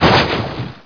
mission_sound_droidhit07.wav